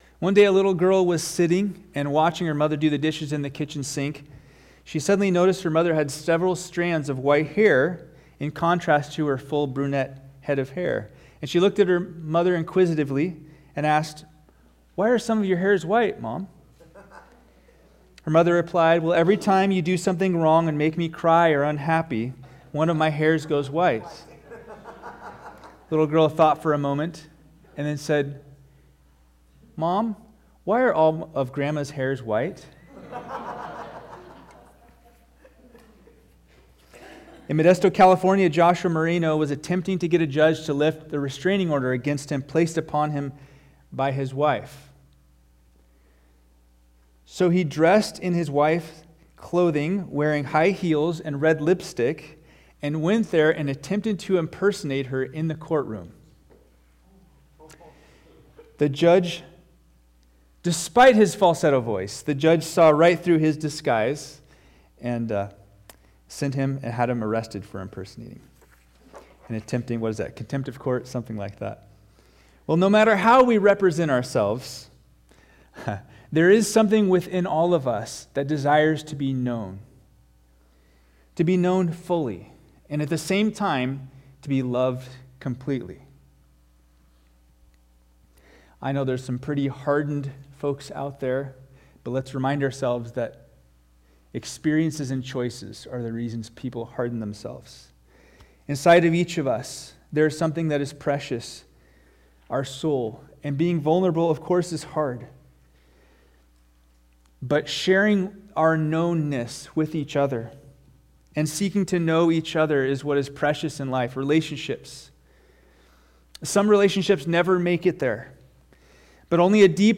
All Sermons Whole-Hearted November 17